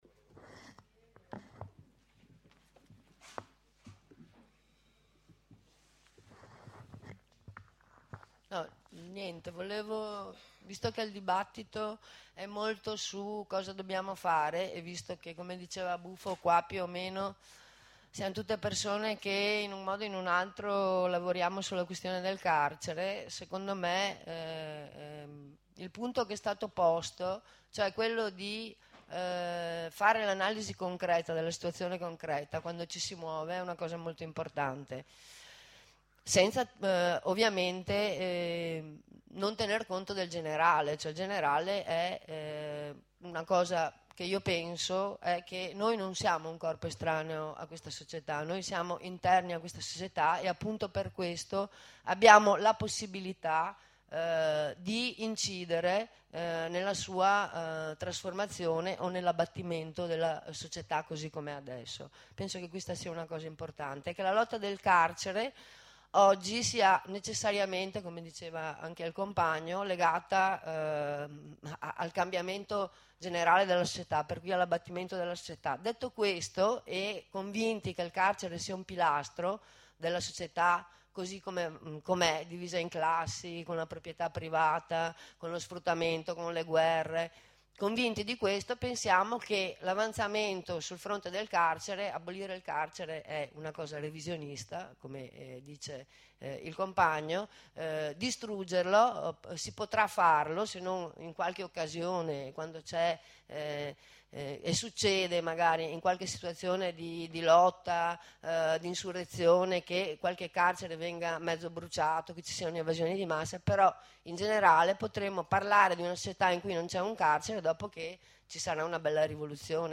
Corrispondenza con un compagno recluso nel CIE di Bari e li’ deportato dopo le rivolte al CIE di Ponte Galeria a Roma
intervista